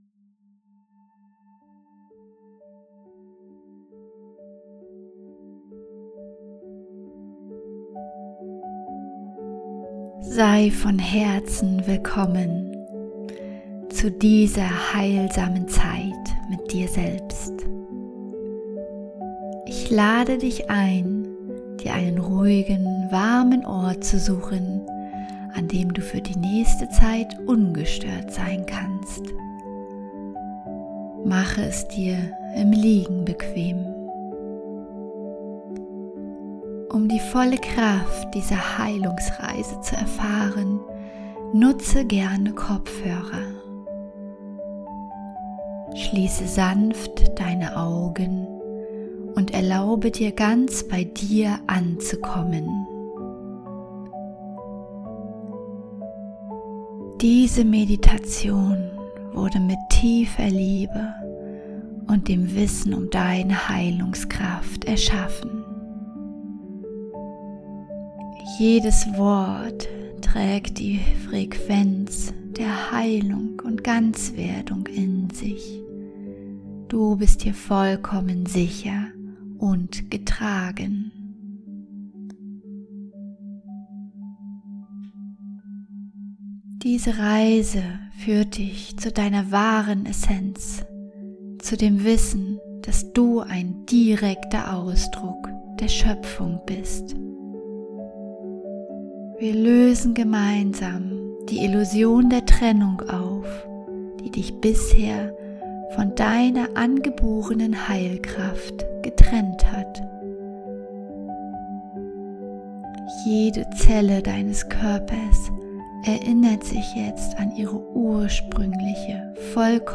Meditation: Aufhebung der Trennung
Der summende Ton im Hintergrund ist der Binaurale Ton, der dein Gehirn in den Theta-Zustand bringt
Wichtige Hinweise: Nutze unbedingt kabelgebundene Kopfhörer , damit die binauralen Beats ihre volle Wirkung entfalten können.